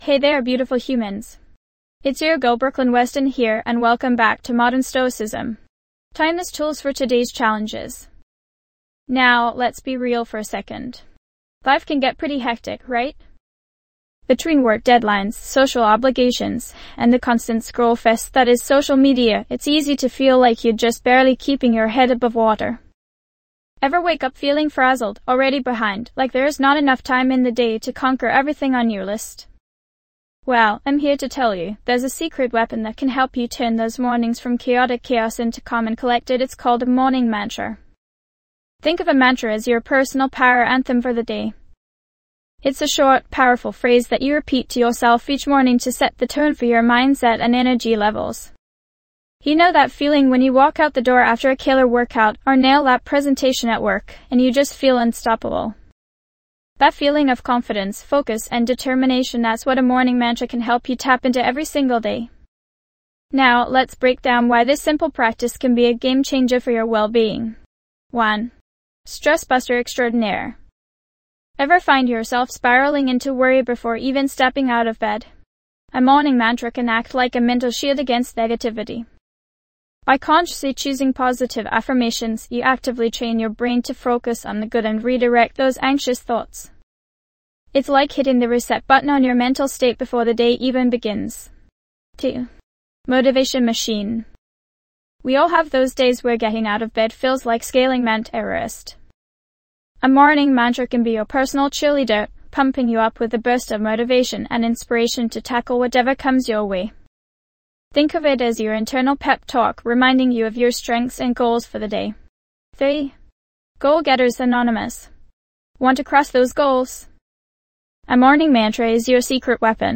- Experience a guided practice to help create your own powerful morning mantra
This podcast is created with the help of advanced AI to deliver thoughtful affirmations and positive messages just for you.